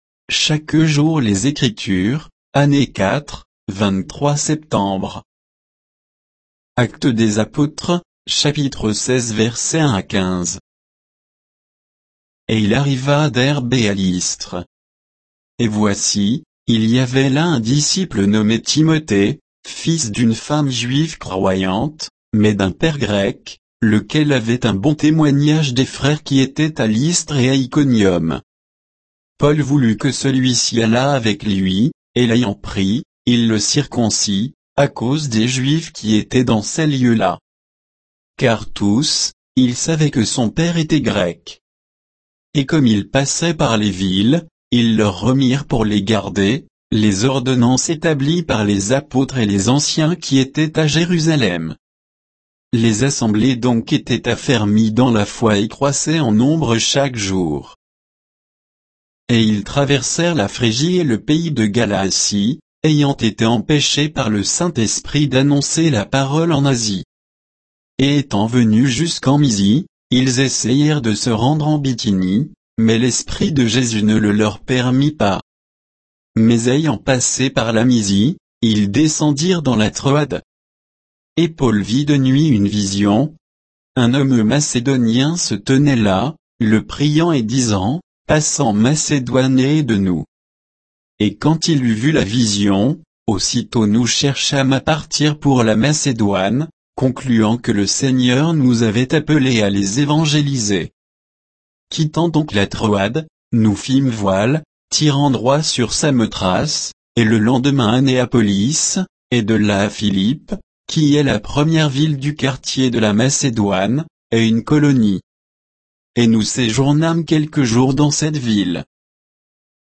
Méditation quoditienne de Chaque jour les Écritures sur Actes 16